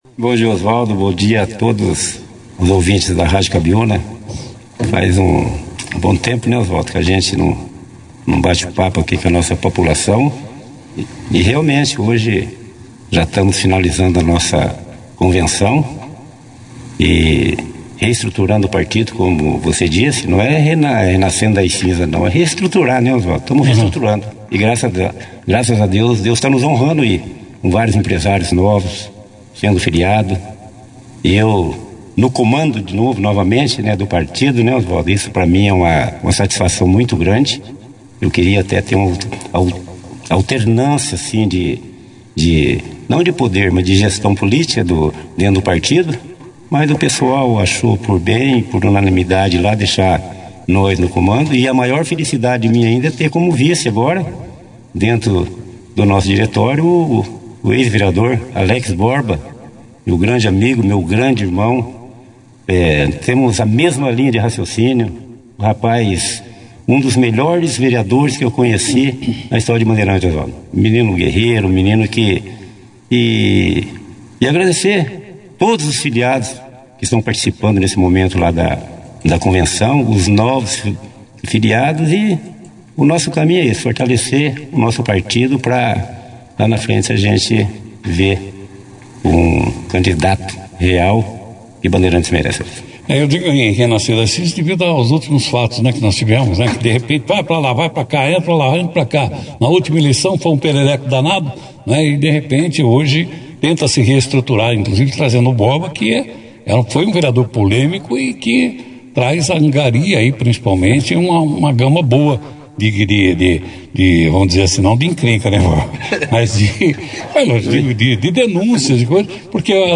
A nova diretoria esteve presente no Jornal Operação Cidade, edição deste sábado, onde falou sobre a convenção partidária e os próximos passos do MDB, tanto no município quanto no cenário estadual.
A entrevista também contou com a participação do ex-prefeito de Tomazina, Flávio Zanrosso, político filiado ao MDB do Paraná.